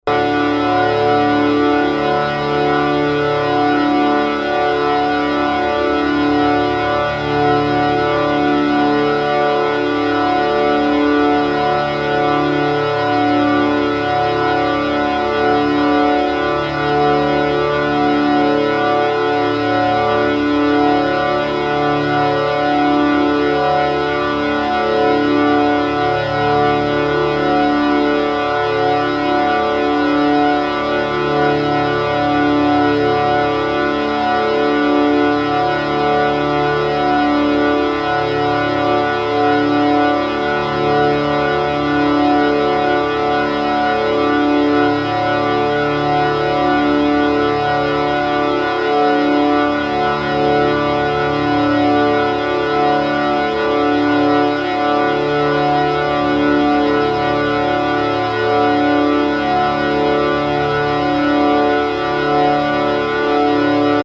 (4 channel sound installation / 2005)
4 turntables on a platform and 4 dubplates with locked grooves
4 dubplates / 4 turntables / 2 mixers / 4 speakers & 1 subwoofer
The main theme of the festival was "acoustic pollution". We decided to take small cuts from various muzak titles as source material and to create a frozen acoustic space. The digital sound material was cut on dubplates as locked grooves. A standing, slowly evolving & intense cluster filled the space at certain timeslots during the festival.